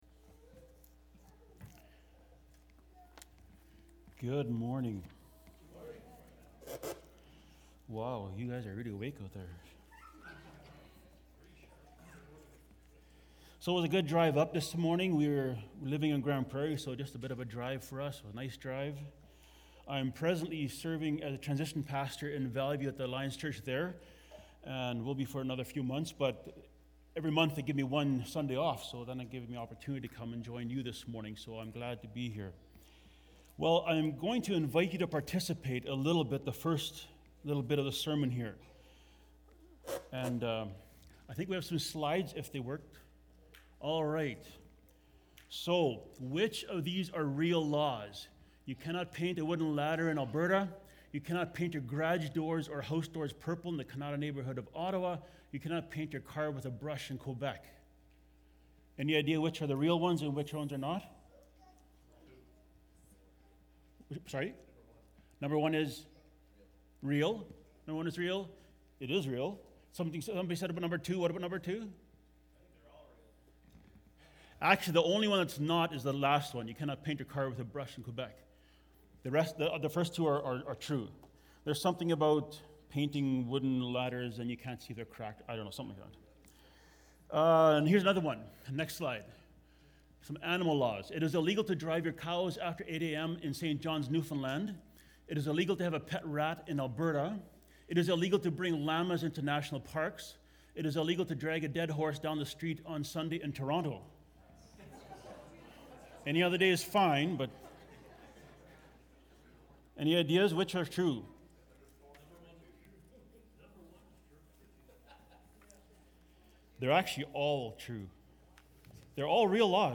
Feb-6-sermon-audio.mp3